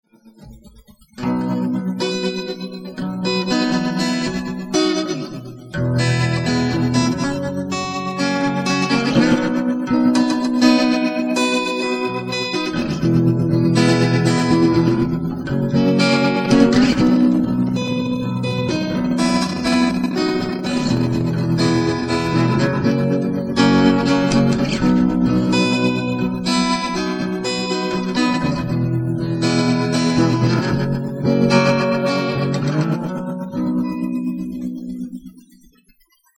Tremolo and Compressor only, I believe